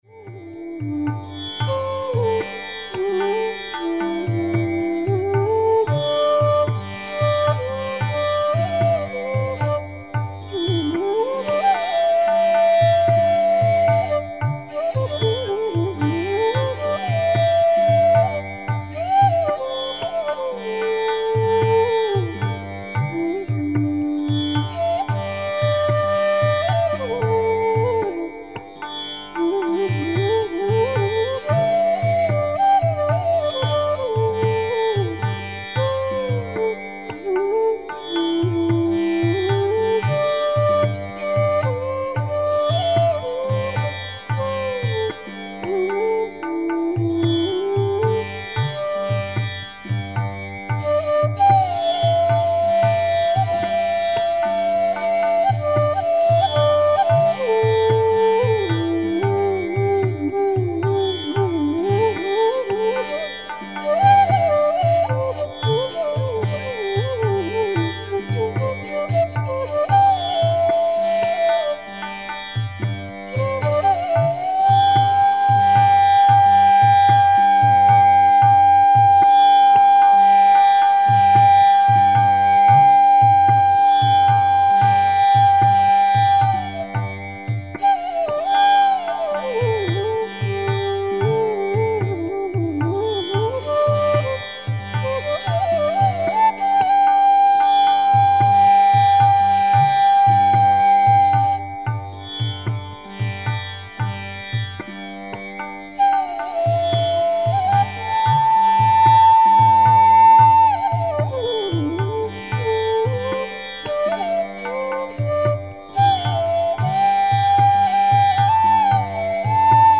Indian Classical Flute Recitals: